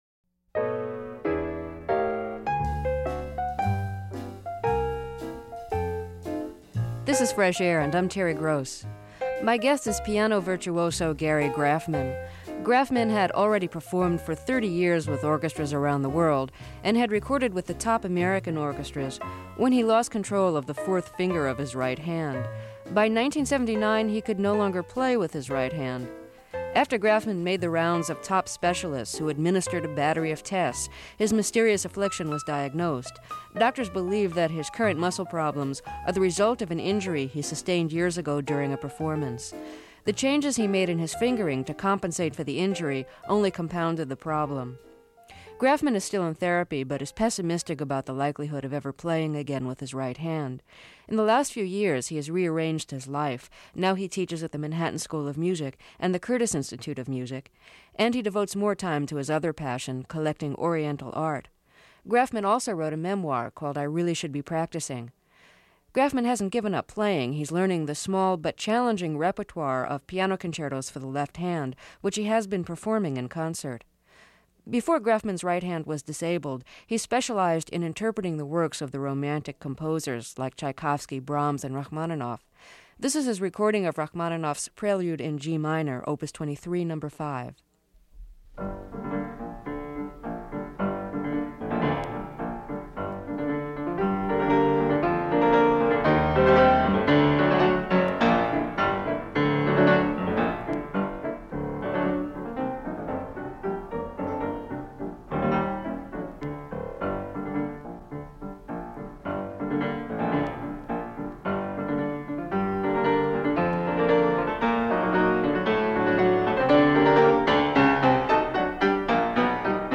Pianists and Keyboard Players | Fresh Air Archive: Interviews with Terry Gross
Singer Judy Collins. She's just written an autobiography; it's titled Trust Your Heart.